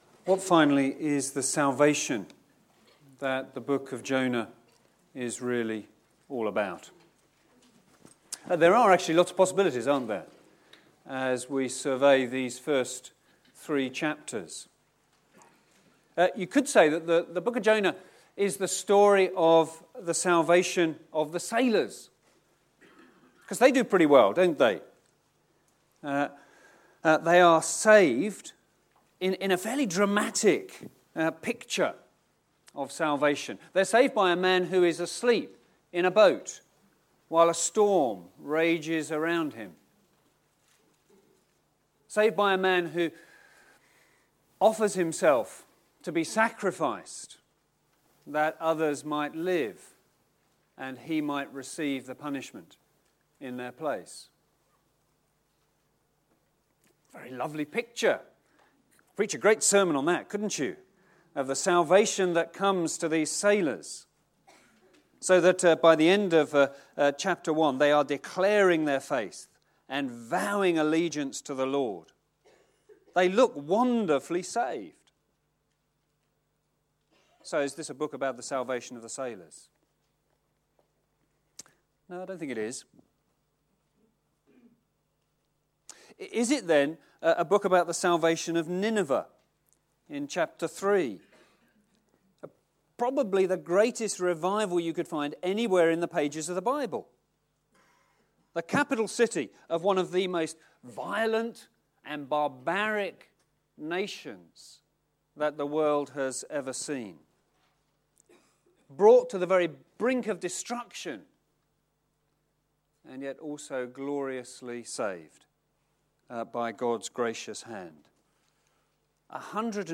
Anger: being cross, being ChristlikeResidential Conference, February 2018